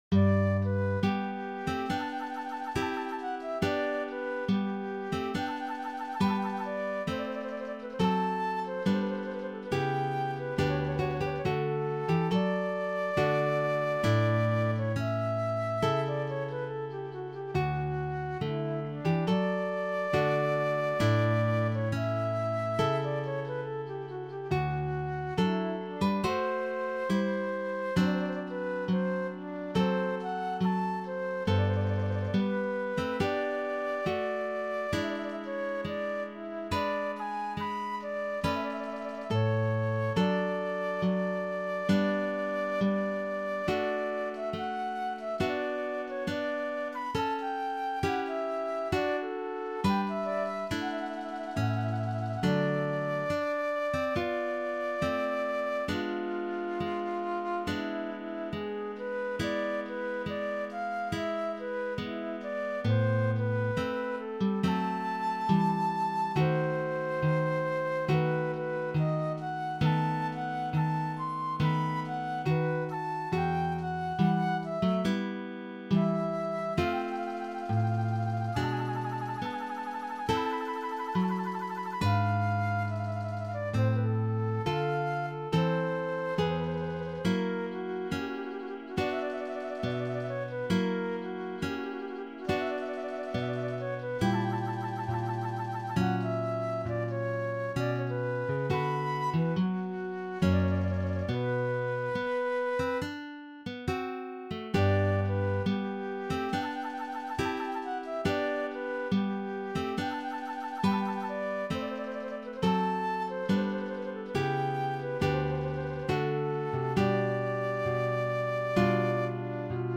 arranged for Fute and Guitar